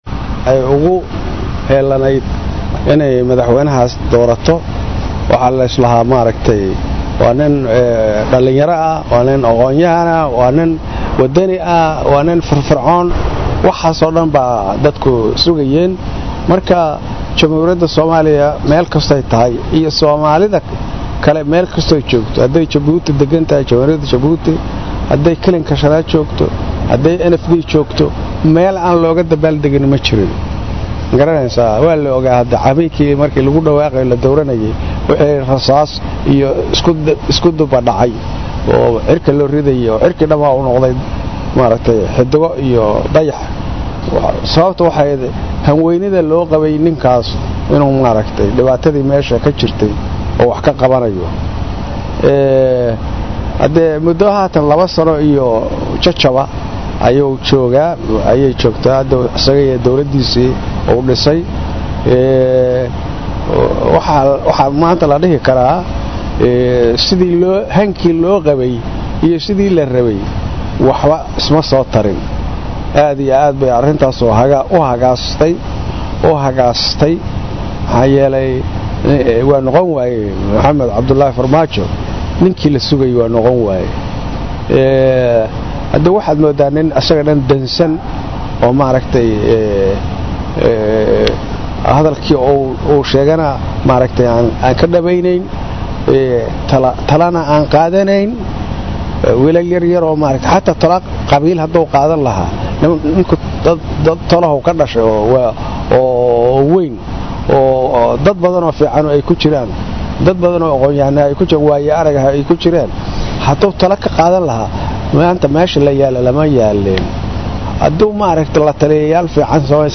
Jenaraar Maxamad Nuur Galaal oo kamid ah saraakishii Dowladii Meleteriga aheyd ee somaaliya oo Wareysi Gaar ah siinayay Radiogalgaduud ayaa Waxa uu ugu horeyn Kahadlay arimo Dhowr ah isagoona si gaar ah u dhaliilay Qaaabka Ay wax u Wado Dowlada uu hugaamiyo Madaxweyne Maxmaed Cabdulaahi Farmaajo sheegayna in ay san muuqanin waxyaabihii Ay Rejeynayeen shacabka somaaliyeed.